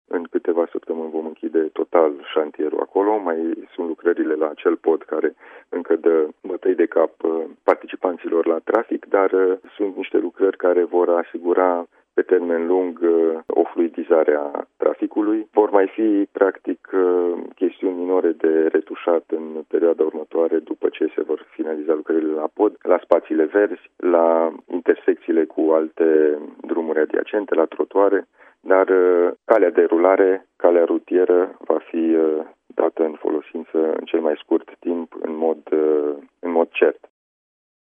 Președintele Consiliului Județean, Alin Nica, a anunțat ast[zi, la Radio Timișoara, că mai trebuie executate câteva lucrări dar șoseaua va fi deschisă traficului în curând.